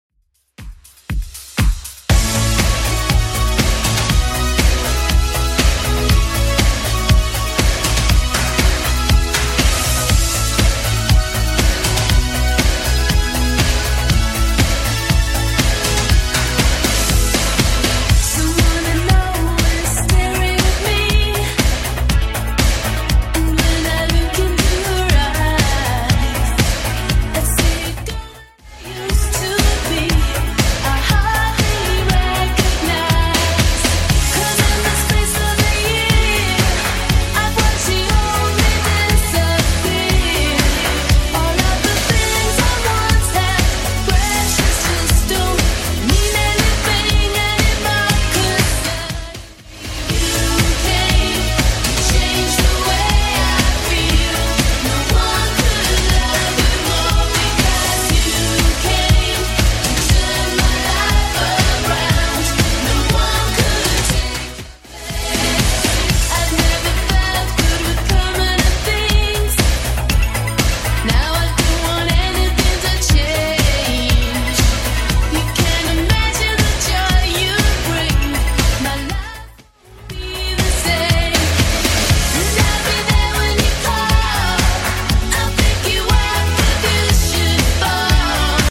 Genre: 70's
BPM: 105